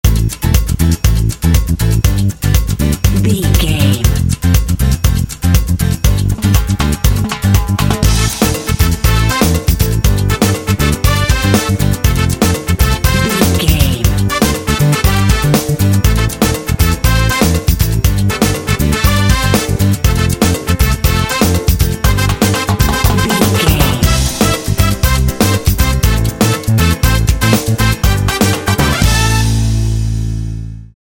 Uplifting
Aeolian/Minor
smooth
lively
energetic
driving
percussion
drums
bass guitar
brass
latin